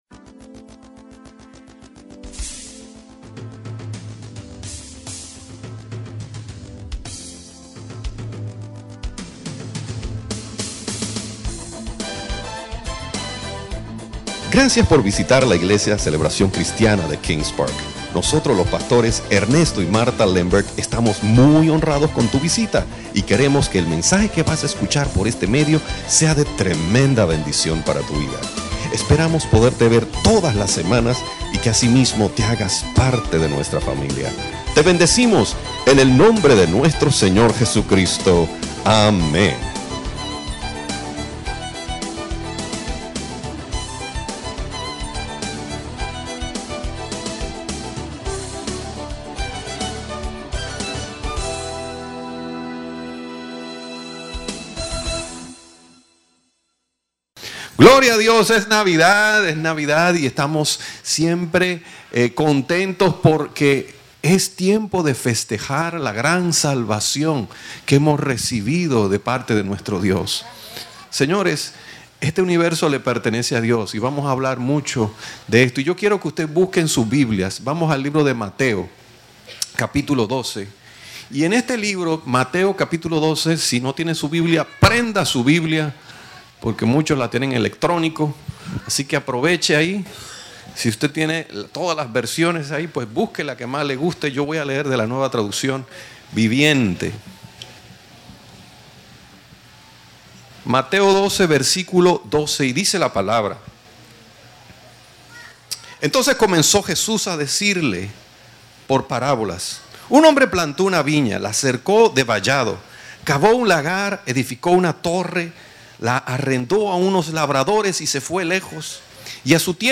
Series: Servicio Dominical